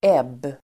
Uttal: [eb:]